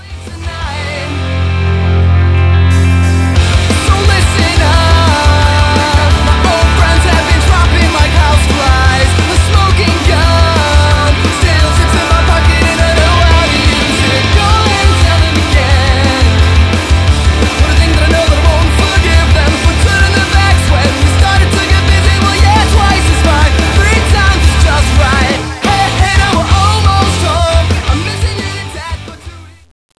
Genre: Punk/Pop